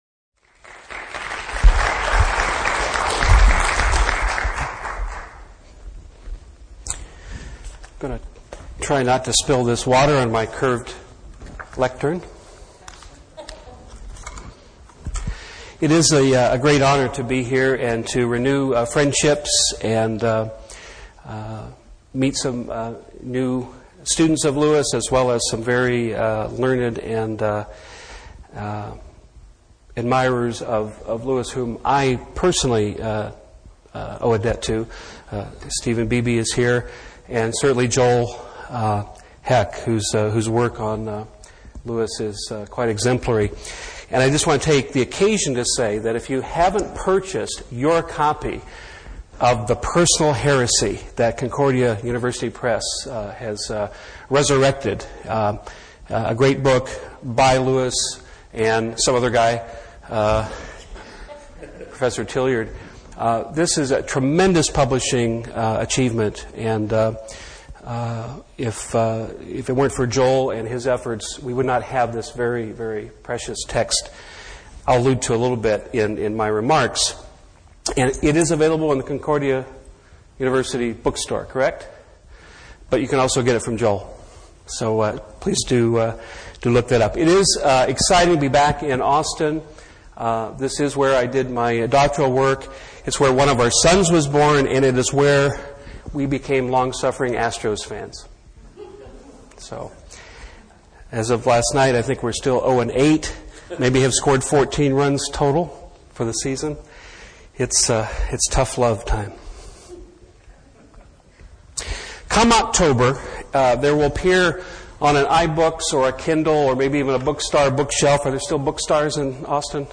Originally delivered as the 4th Annual Concordia University (Austin, TX) Lecture on C. S. Lewis on April 15th, 2010.